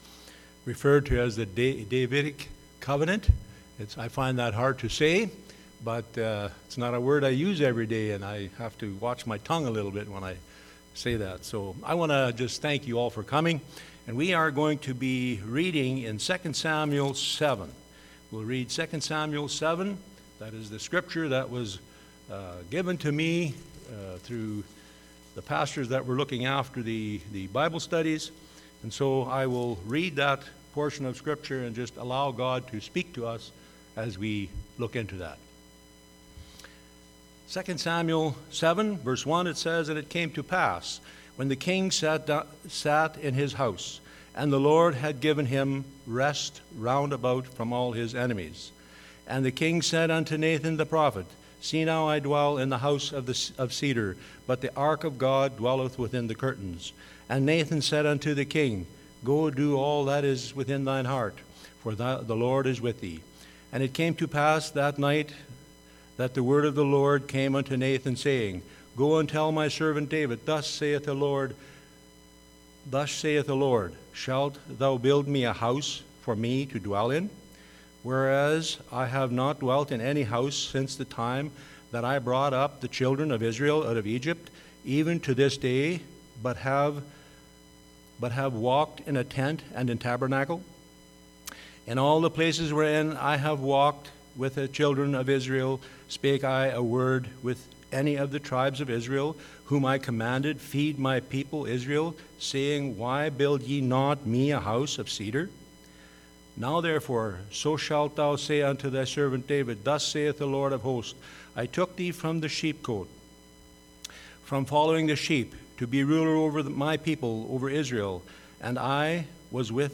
Church Bible Study – Covenants – Davidic
Passage: 2 Samuel 7 Service Type: Church Bible Study